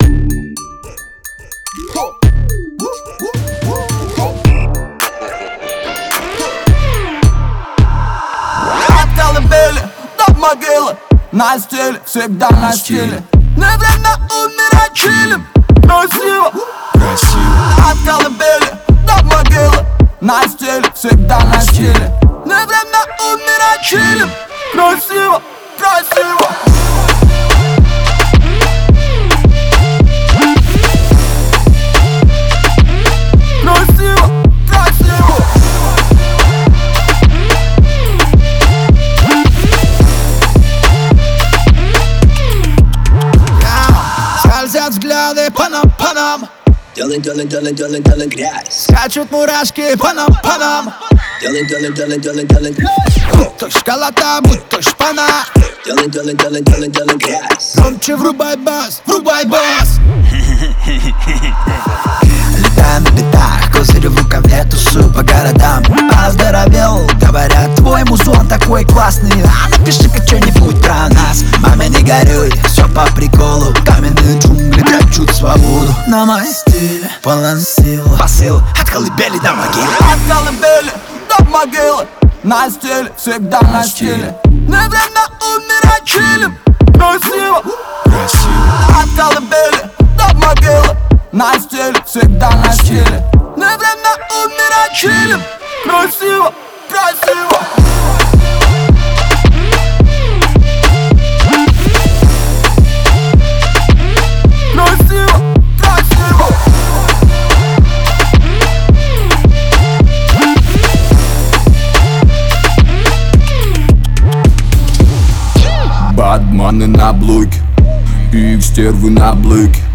это трек в жанре рэп